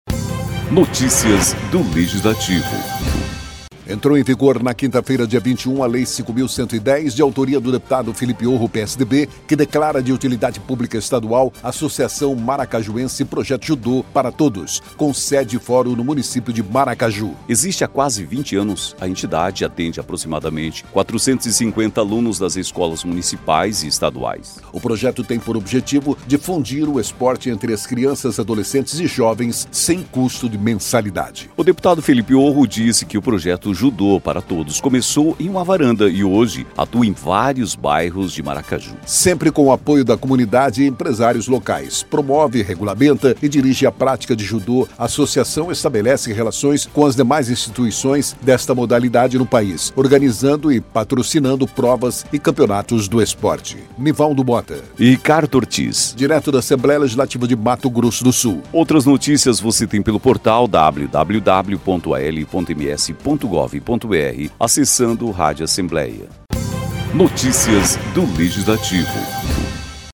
Locução